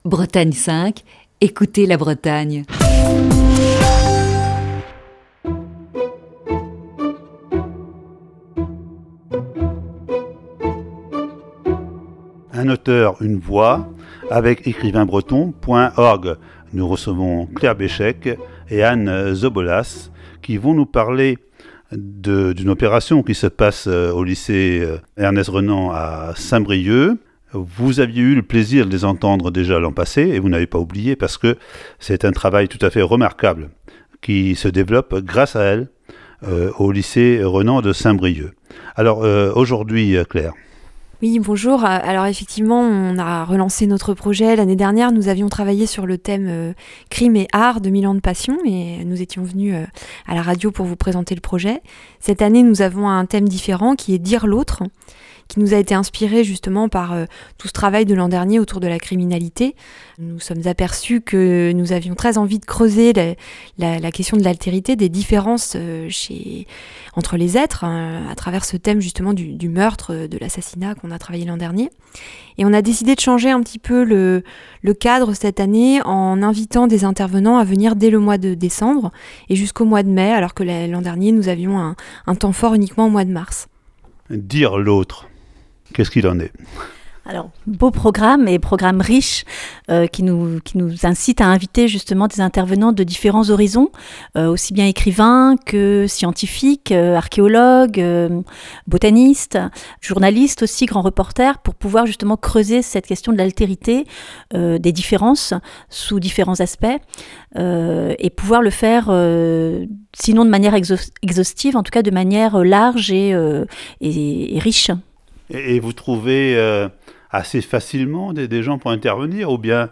Ce lundi, première partie de cet entretien.